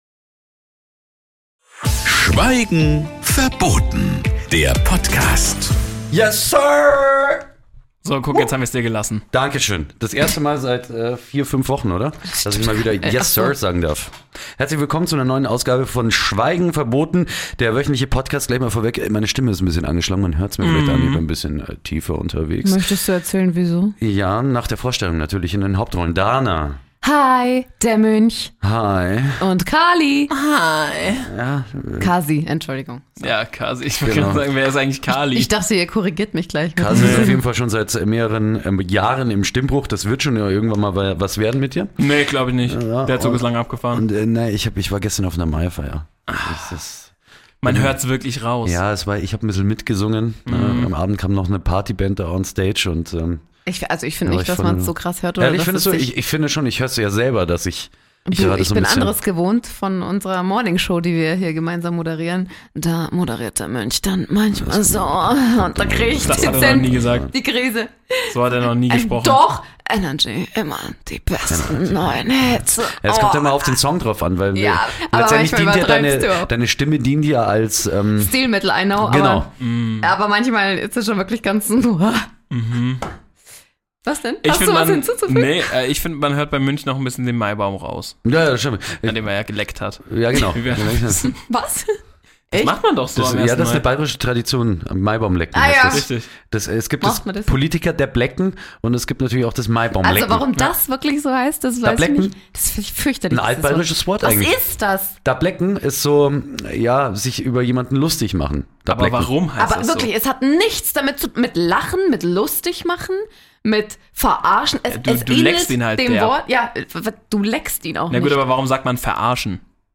Heute gibt's Schweigen Verboten auf Bayrisch!